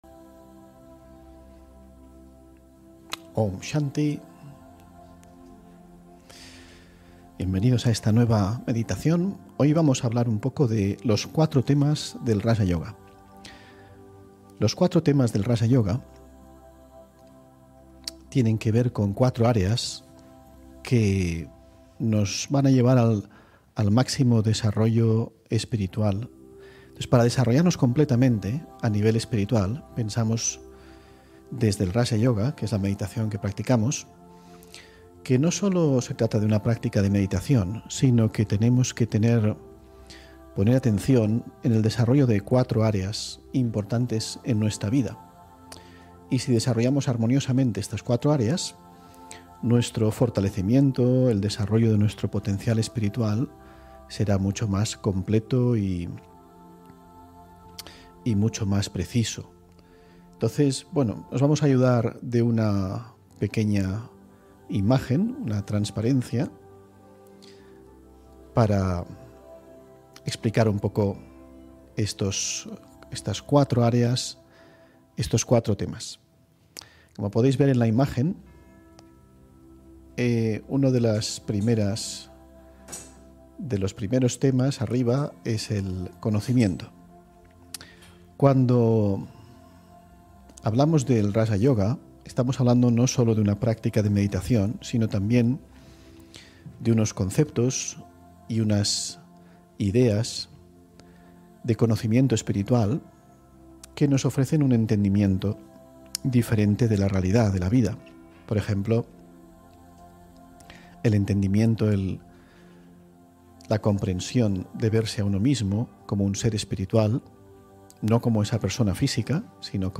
Meditación Raja Yoga y charla: Los 4 temas del Raja Yoga (27 Julio 2021) On-line desde Madrid